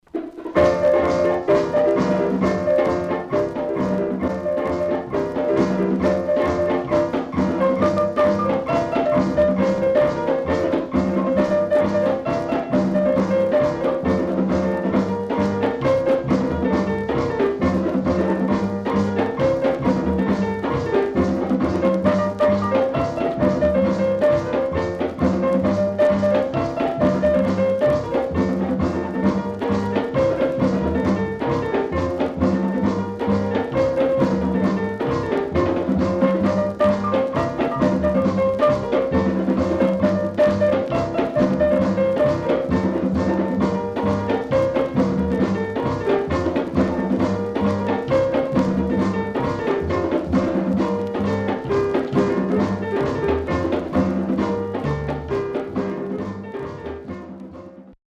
BOLERO, MAMBO,CAYPSO等の良曲多数収録。
SIDE B:所々チリノイズ入ります。